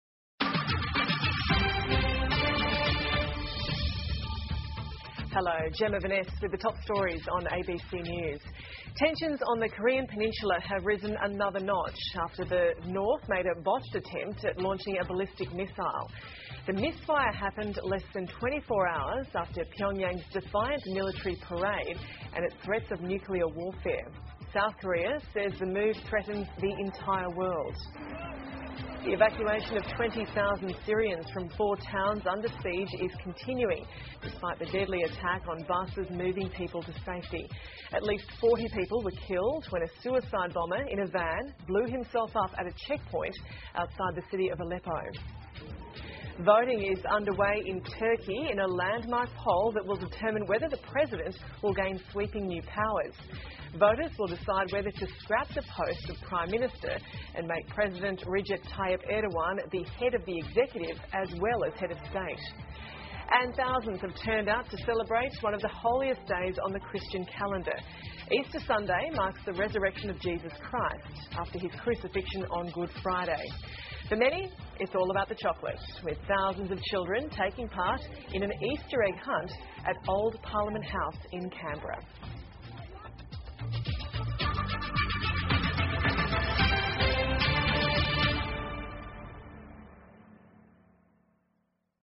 澳洲新闻 (ABC新闻快递) 朝鲜再射导弹失败告终 叙利亚撤离人员车队遇袭 听力文件下载—在线英语听力室